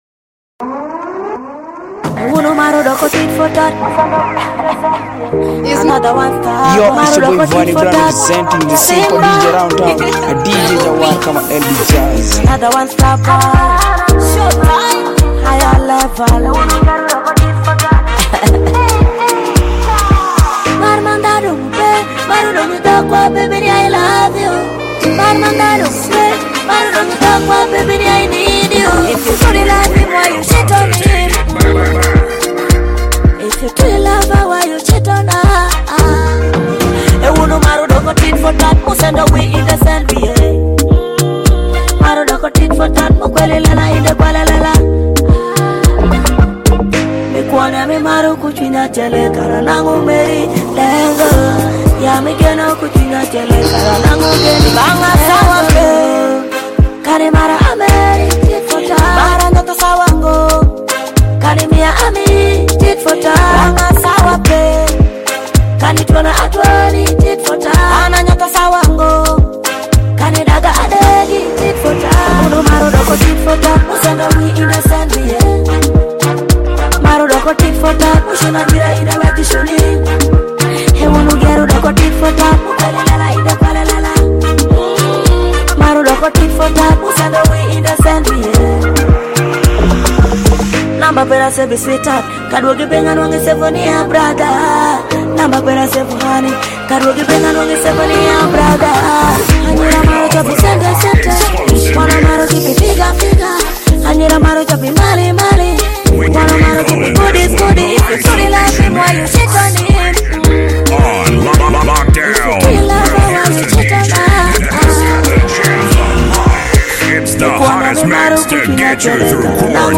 Latest Alur Music